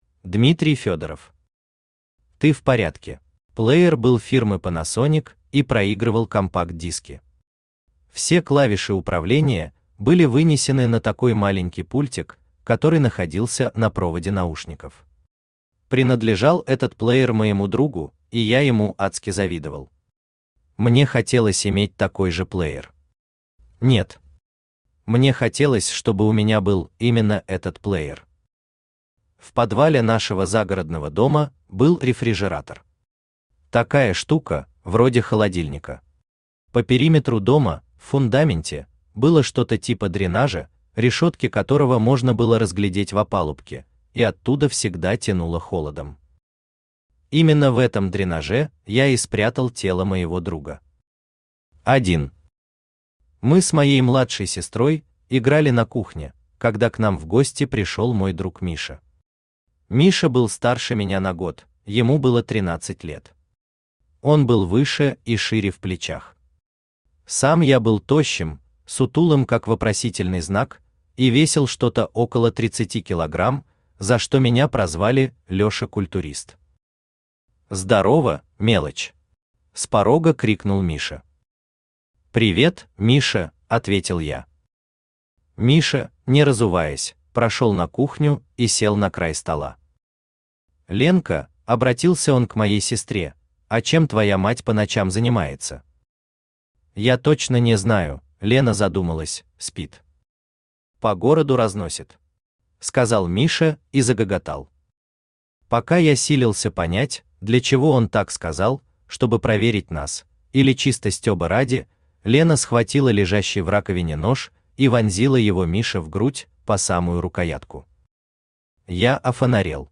Аудиокнига Ты в порядке | Библиотека аудиокниг
Aудиокнига Ты в порядке Автор Дмитрий Сергеевич Федоров Читает аудиокнигу Авточтец ЛитРес.